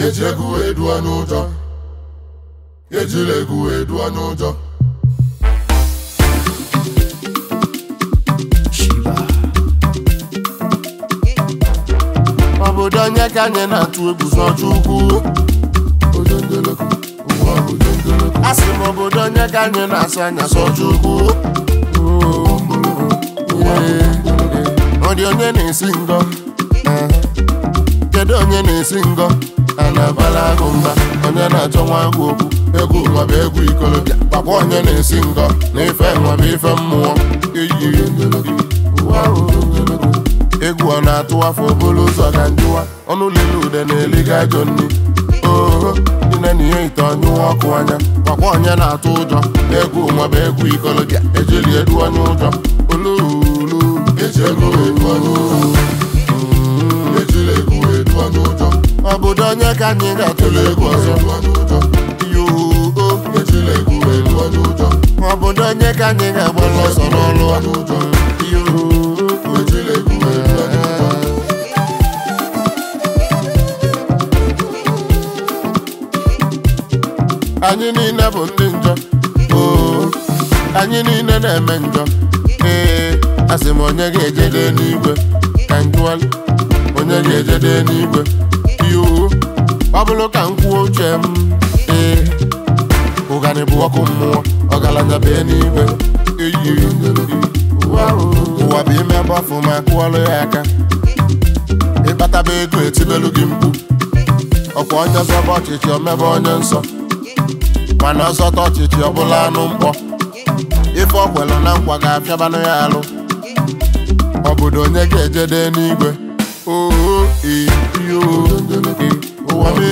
Talented Highlife Duo and songwriter